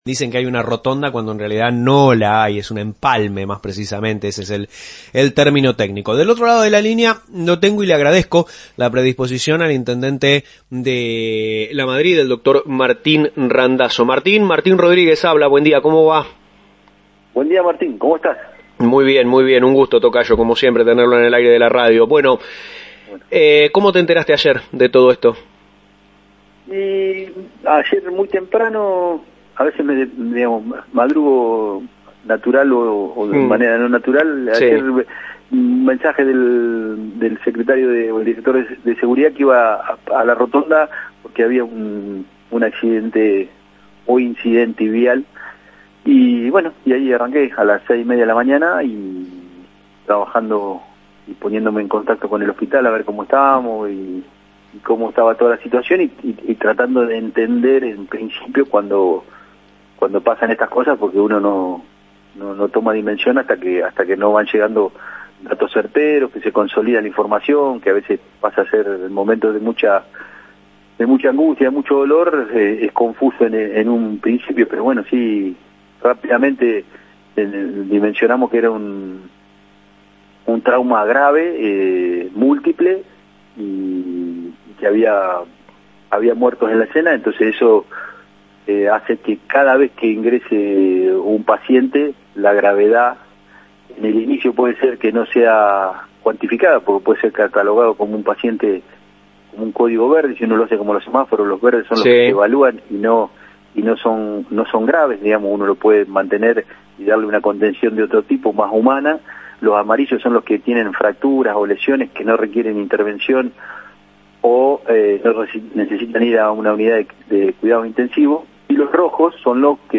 El intendente Martín Randazzo se expresó este lunes en LU32, tras el despiste del ómnibus de este domingo, que dejó cinco fallecidos. Indicó que las víctimas fatales murieron en el lugar de los hechos.